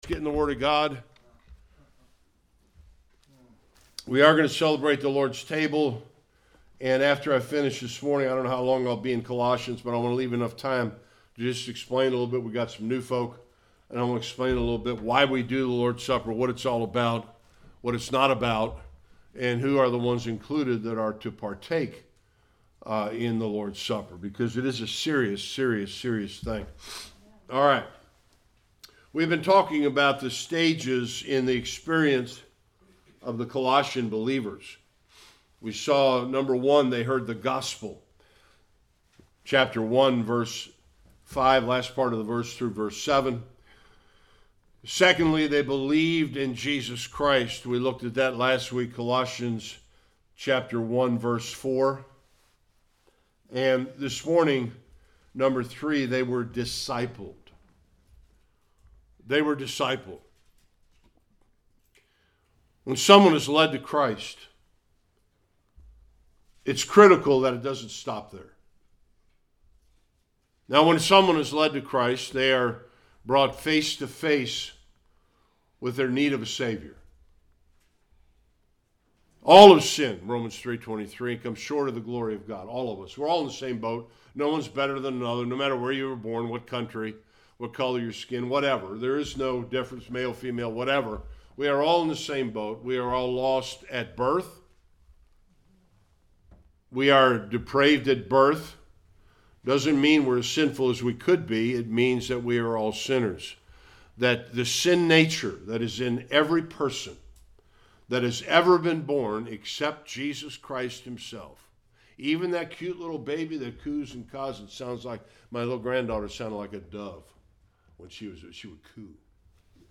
6-8 Service Type: Sunday Worship The faithfulness of the believers at Colossae was seen in their faith